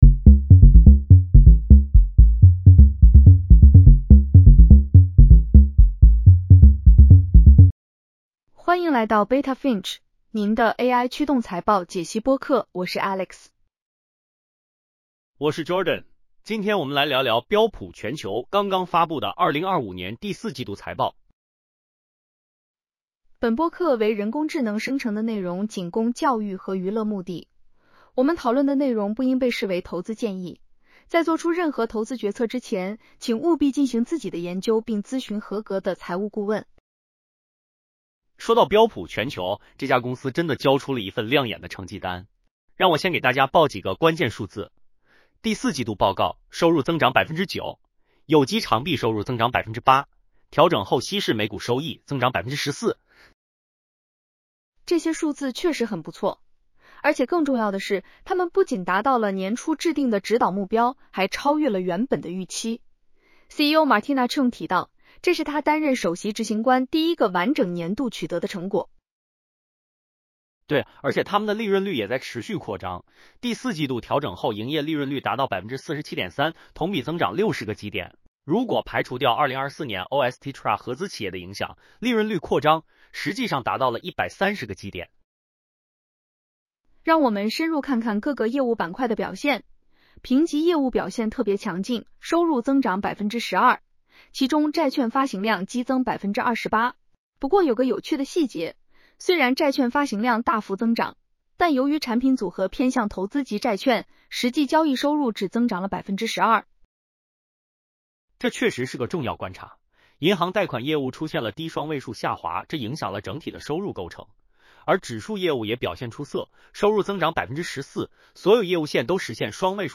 Beta Finch播客脚本：标普全球Q4 2025财报分析